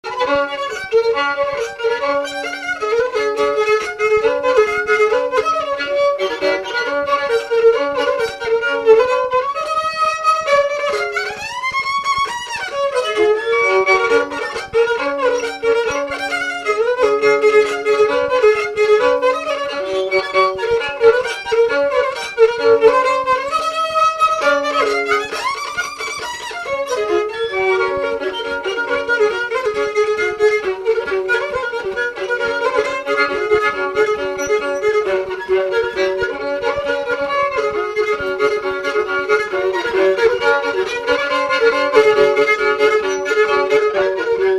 Figure de quadrille
Localisation Salazie
Résumé Instrumental
danse : quadrille
Pièce musicale inédite